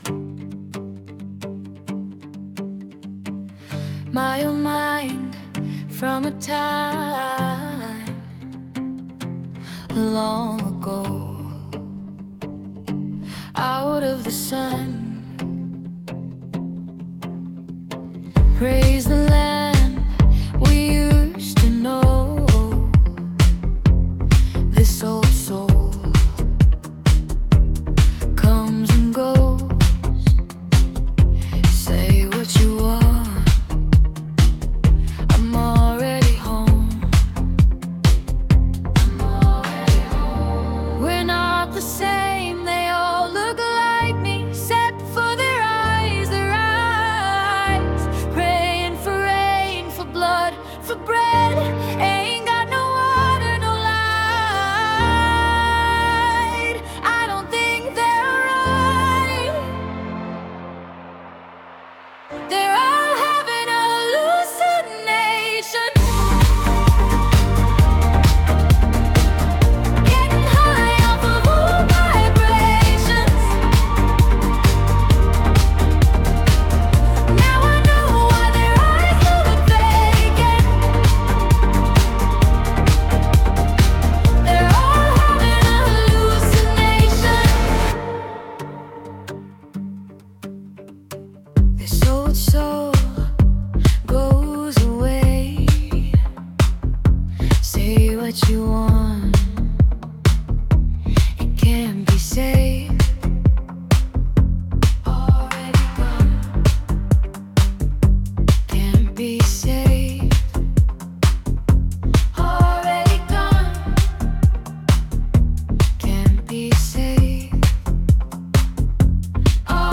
Suno Ai music.